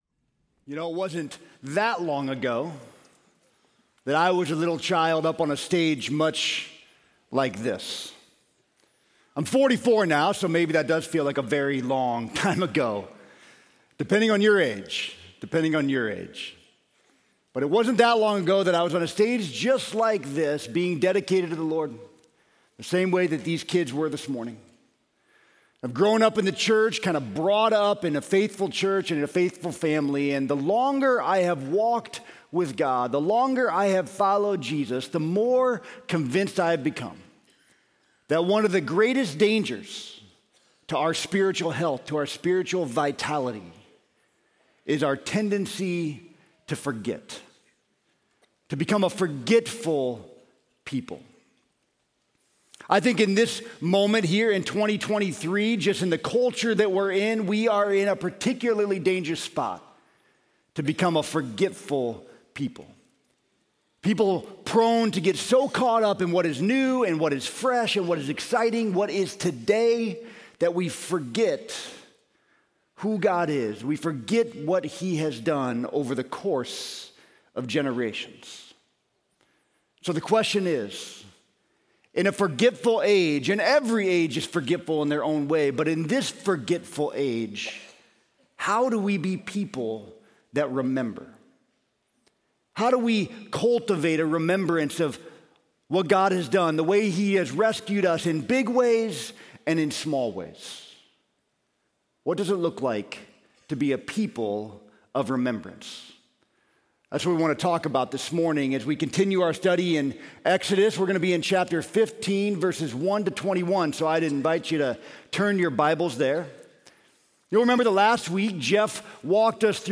Sermon: Redemption Song